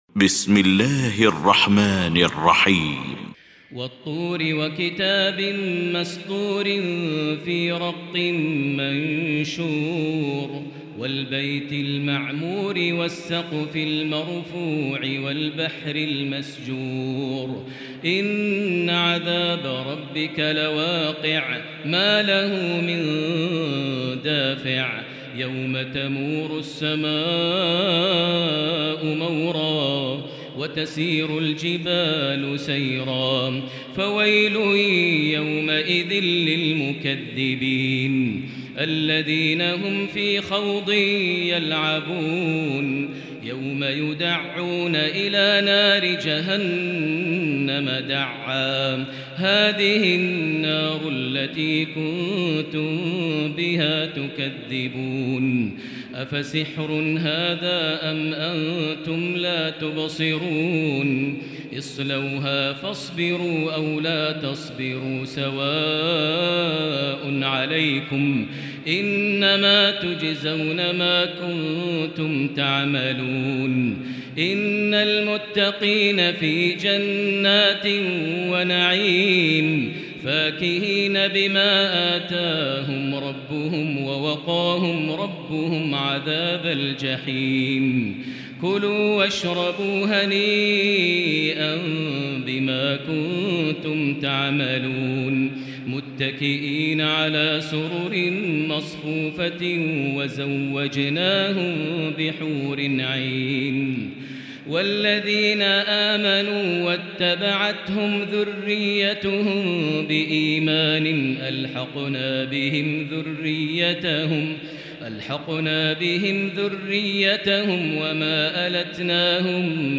المكان: المسجد الحرام الشيخ: فضيلة الشيخ ماهر المعيقلي فضيلة الشيخ ماهر المعيقلي الطور